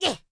Cat Attack Sound Effect
Download a high-quality cat attack sound effect.
cat-attack-3.mp3